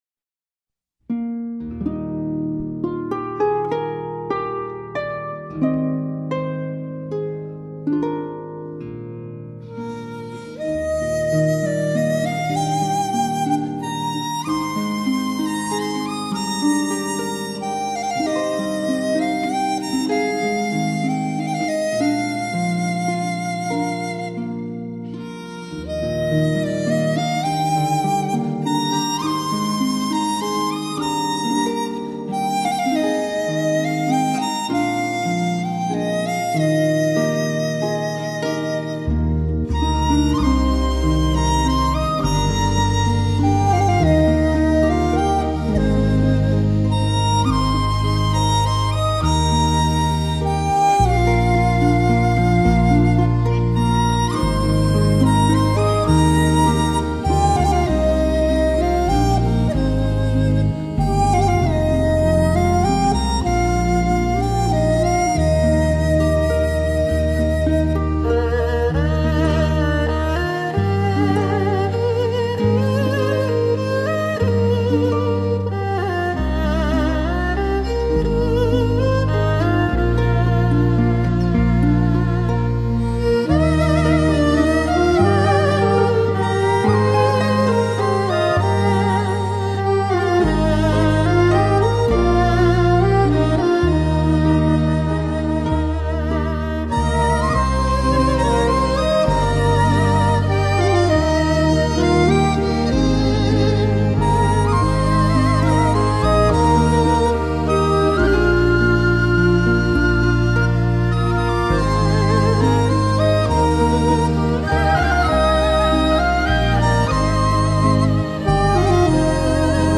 东西方音乐的融合